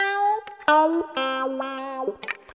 64 GUIT 1 -L.wav